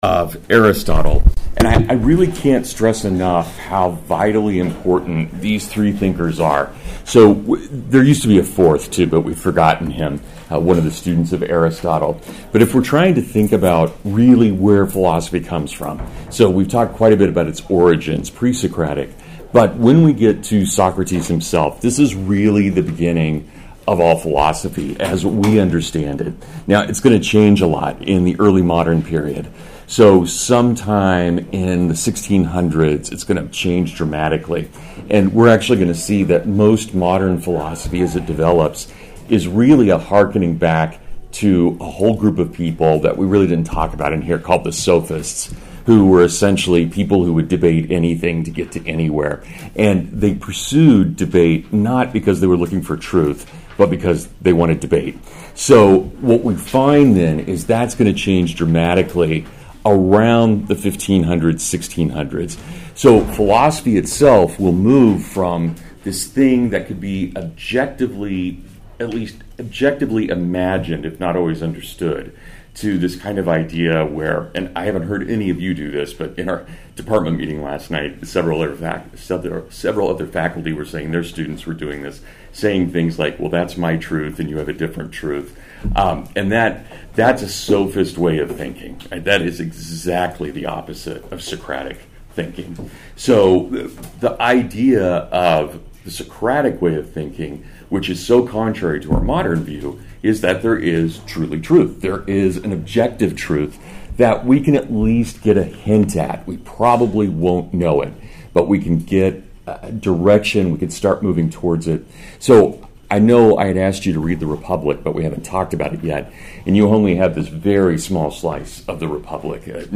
Aristotle (Full Lecture)